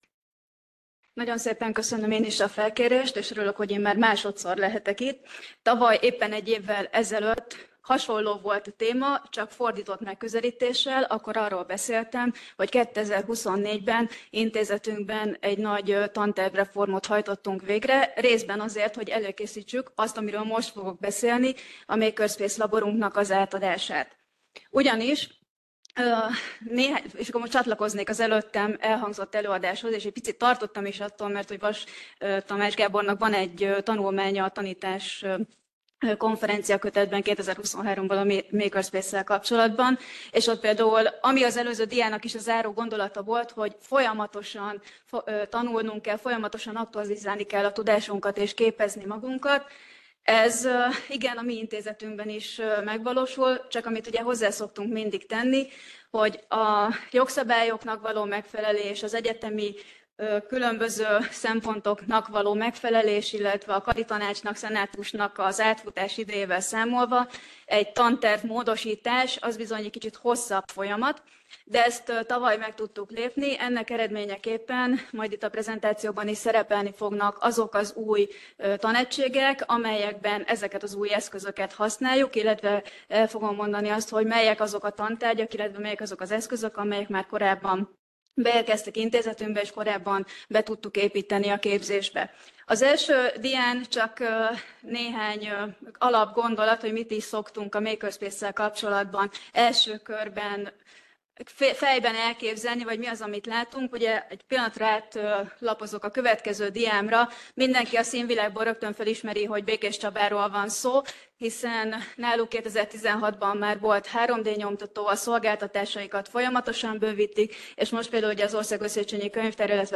Elhangzott a Központi Statisztikai Hivatal Könyvtár és a Magyar Könyvtárosok Egyesülete Társadalomtudományi Szekciója Szakkönyvtári seregszemle 2025 című